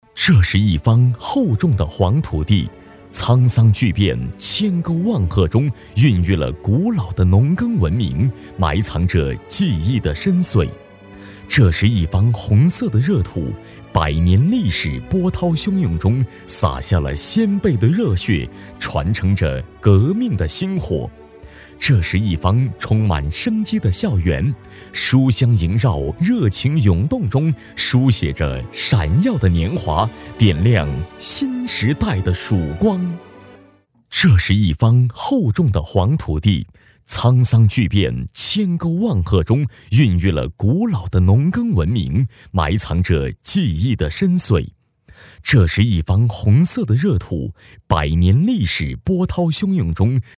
大气浑厚男中音，大气激情。擅长专题汇报，记录片，宣传片,mg等题材。作品：清华六一党支部。